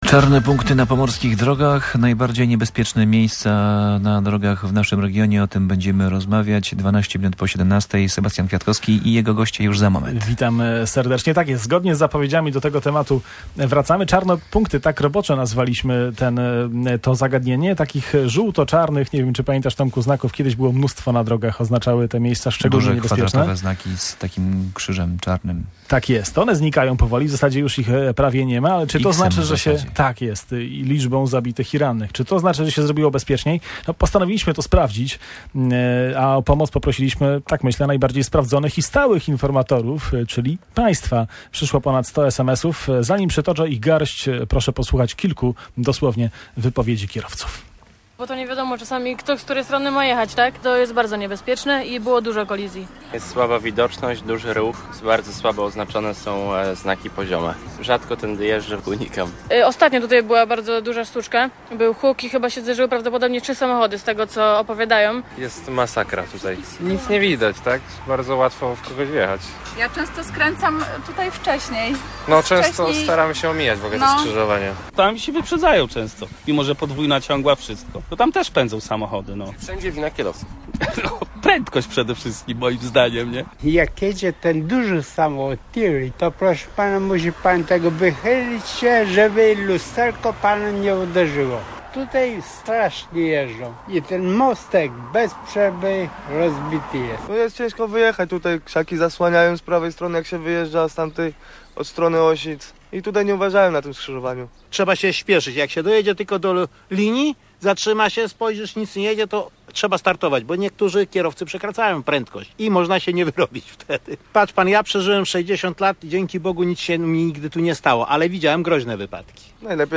Mniej „czarnych punktów” na Pomorzu to znak, że na drogach zrobiło się bezpieczniej? Zapytaliśmy ekspertów.